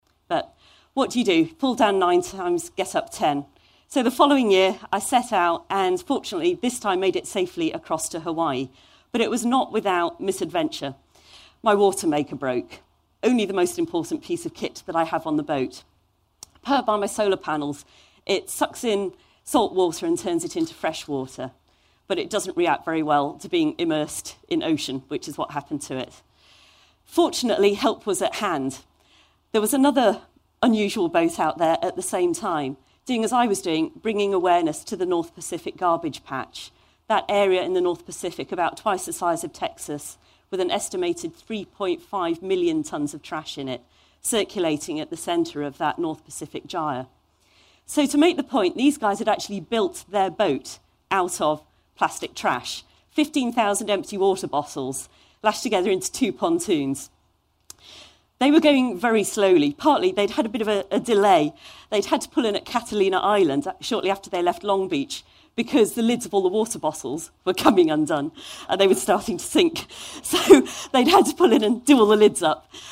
TED演讲：我为什么划船横渡太平洋(7) 听力文件下载—在线英语听力室